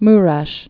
(mrĕsh) or Mu·reşul (mrə-sl, mrĕ-shl)